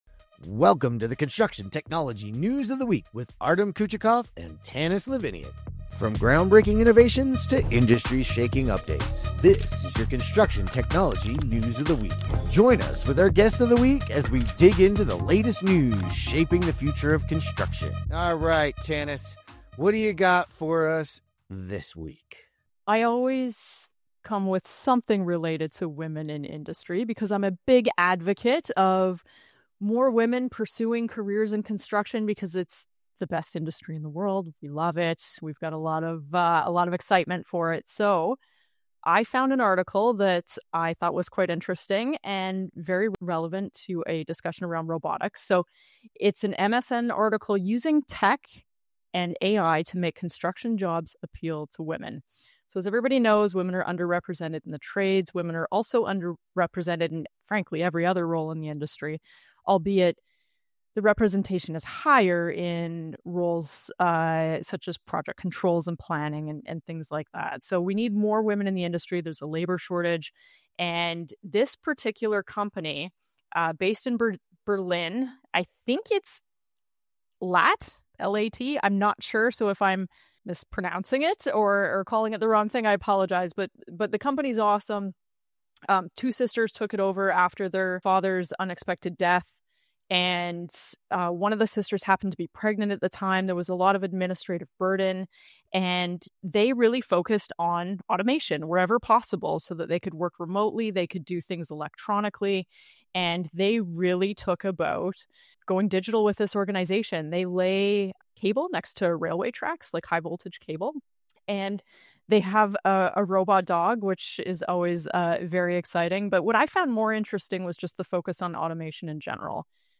Whether you're a techie, contractor, or startup founder, this conversation is packed with takeaways.